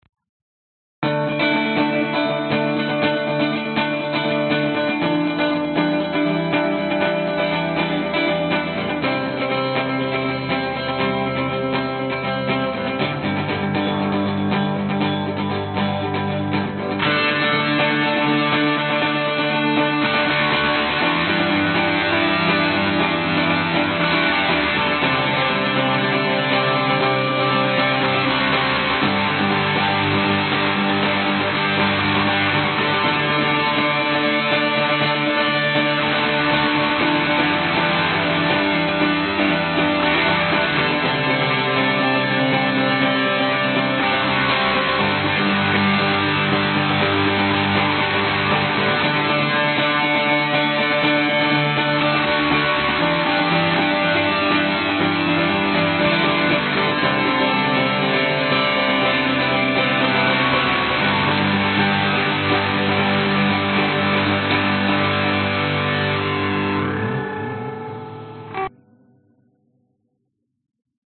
描述：一种U2/新秩序风格的吉他东西。
一如既往的一次性录音，请欣赏
标签： 和弦 电吉他 韵律
声道立体声